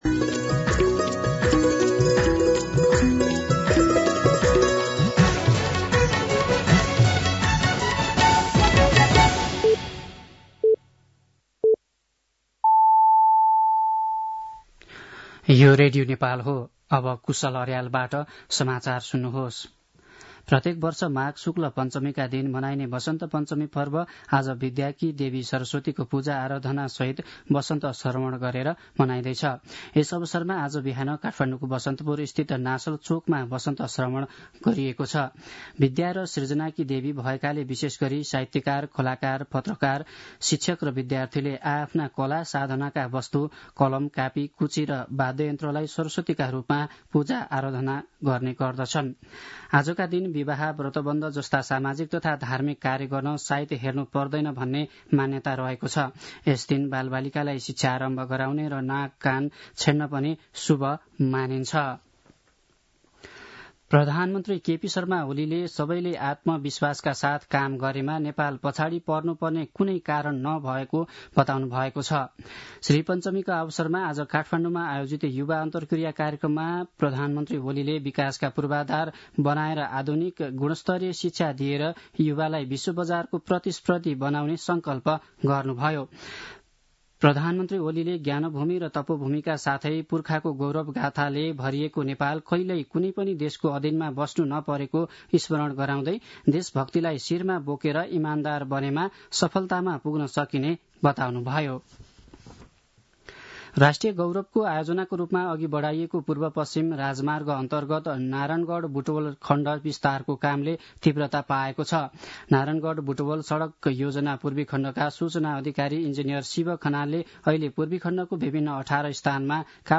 साँझ ५ बजेको नेपाली समाचार : २२ माघ , २०८१
5-PM-Nepali-News-10-21.mp3